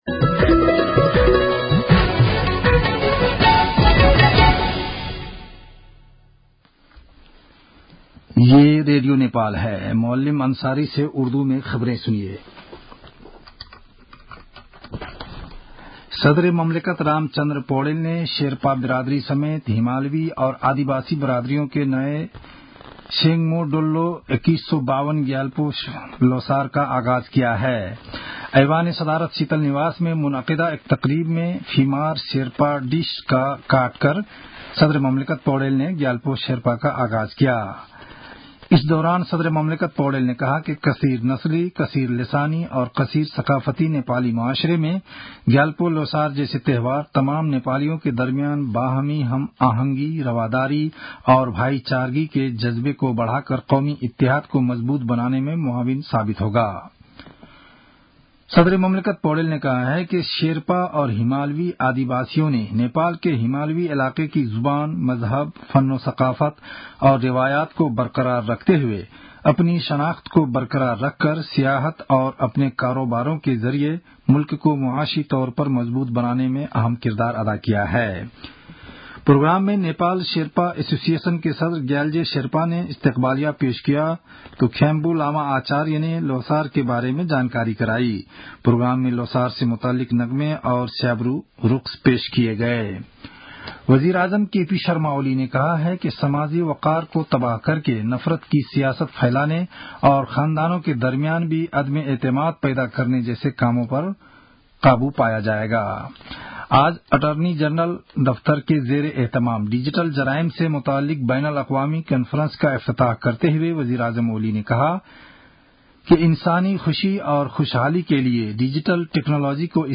उर्दु भाषामा समाचार : १६ फागुन , २०८१